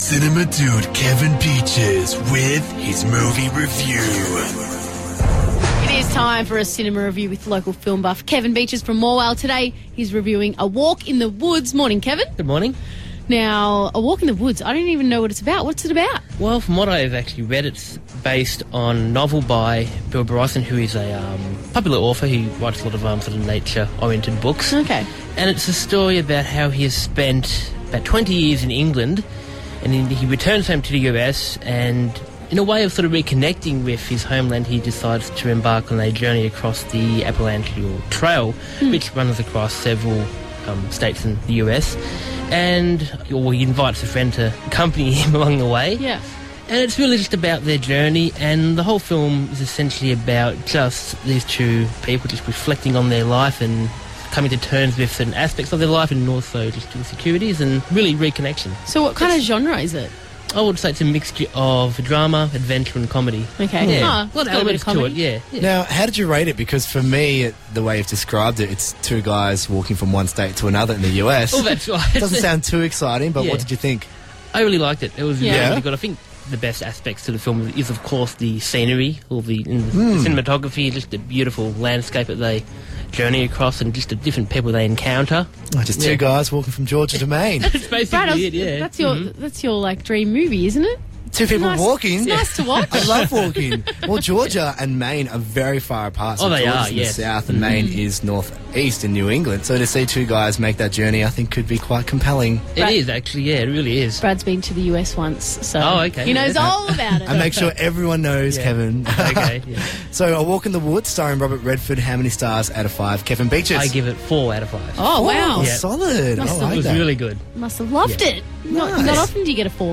Reviewed on Star FM Gippsland